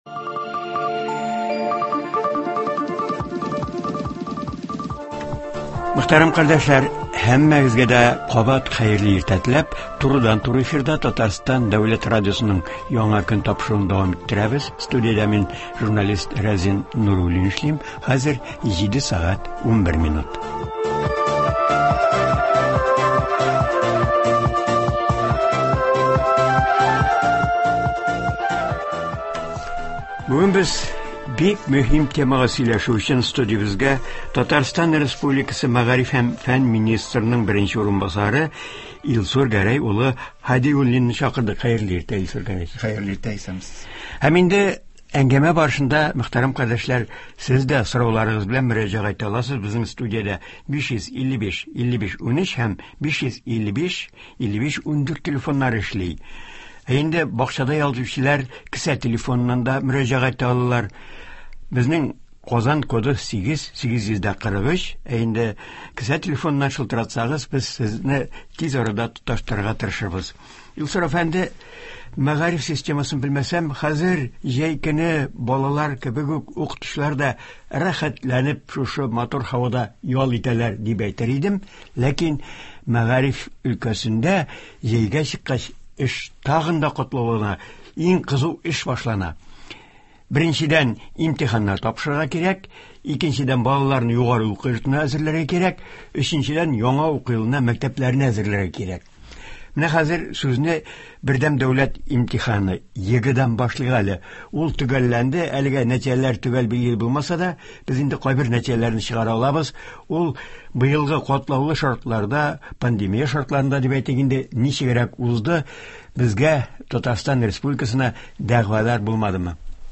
Республикабыз мәктәпләрендә Бердәм дәүләт имитиханнары (БДИ) тапшыру төгәлләнде. Аны оештыру, имтихан нәтиҗәләре, югары баллар алган укучылар турында, шулай ук мәктәп яны һәм шәһәр яны лагерьларында аларның ялын оештыру хакында турыдан-туры эфирда Татарстан мәгариф һәм фән министрының беренче урынбасары Илсур Һадиуллин сөйләячәк, тыңлаучыларны кызыксындырган сорауларга җавап бирәчәк.